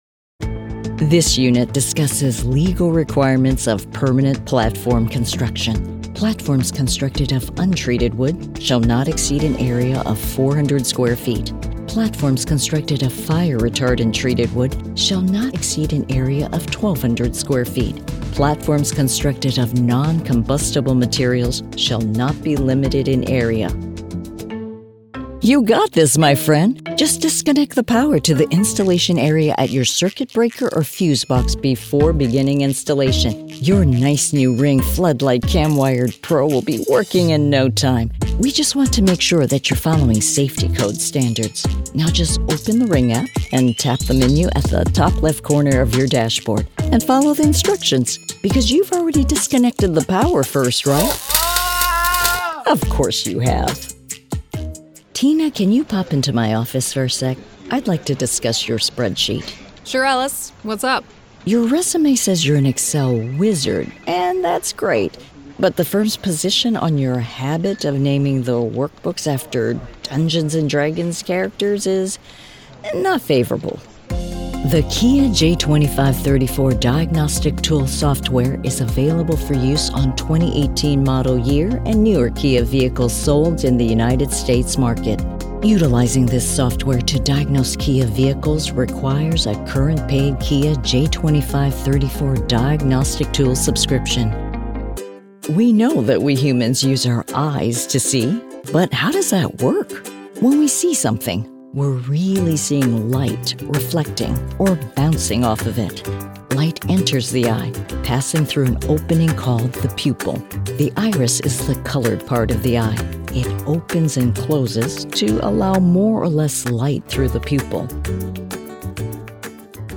E LEARNING Reel